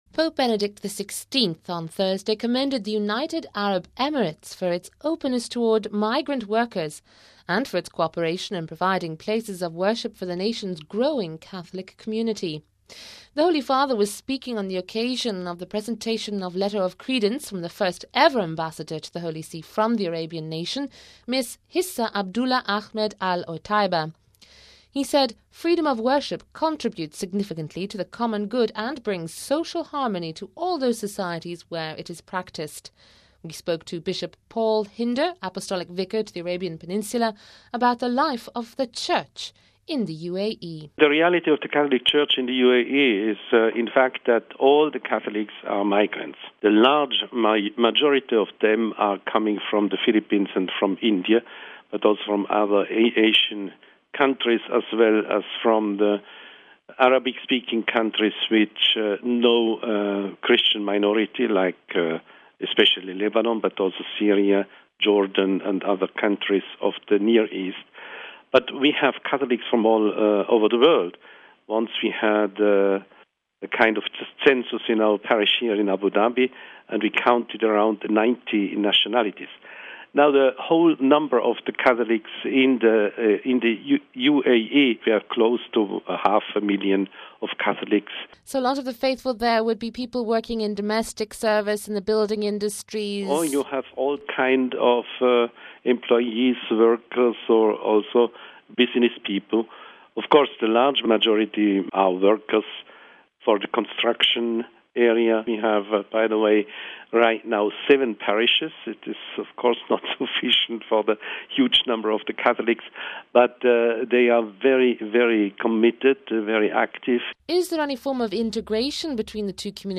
We spoke to Bishop Paul Hinder, Apostolic Vicar to the Arabian Peninsula, about the life of the Church in the UAE.